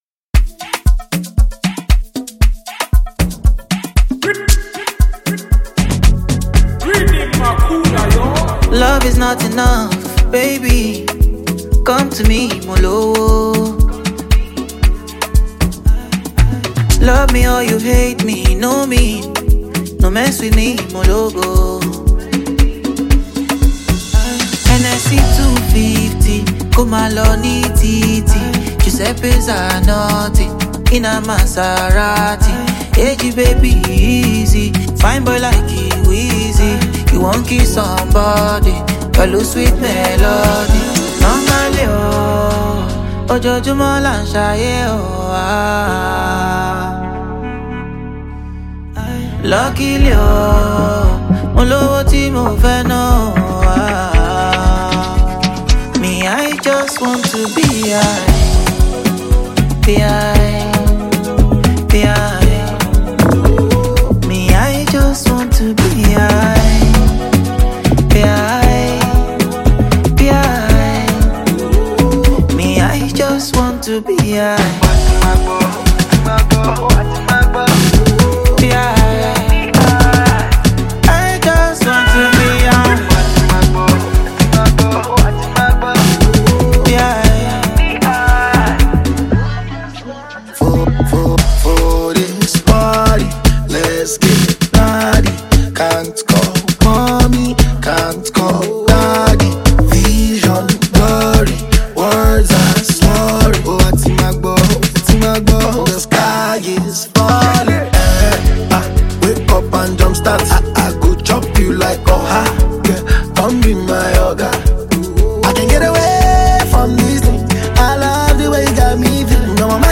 Afrobeat record